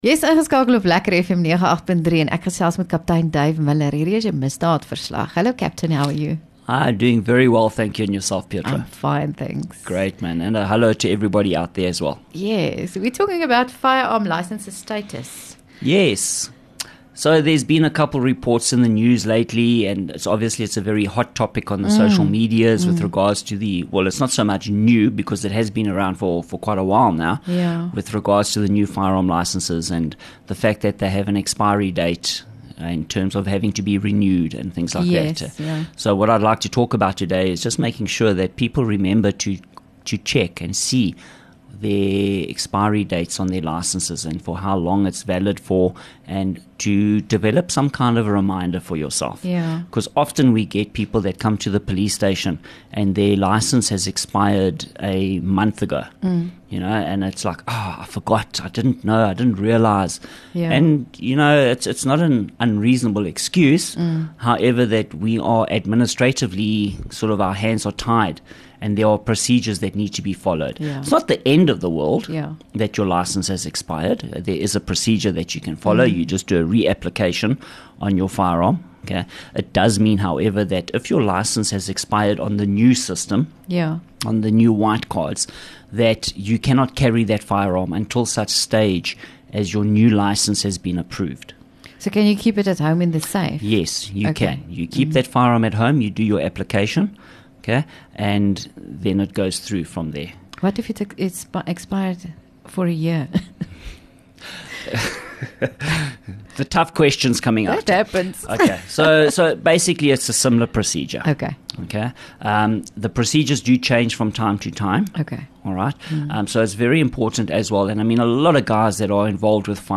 LEKKER FM | Onderhoude 2 Apr Misdaadverslag